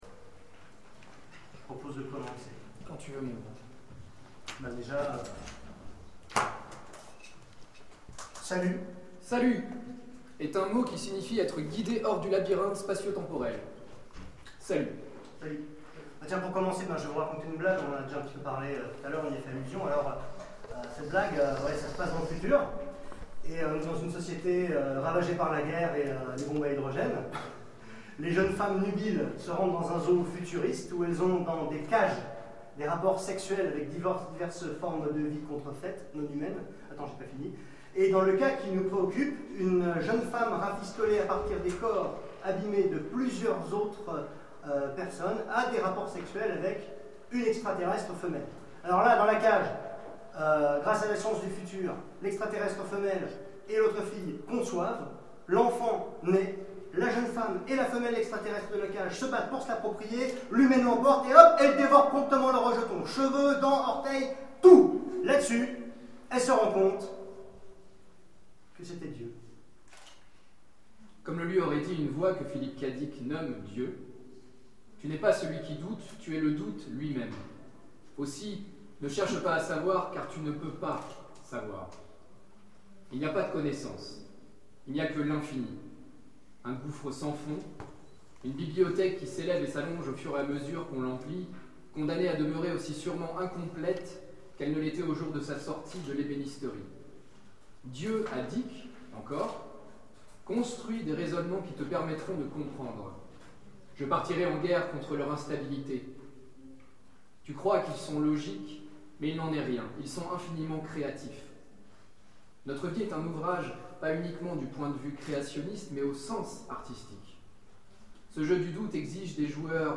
Mots-clés Philip K.Dick Conférence Partager cet article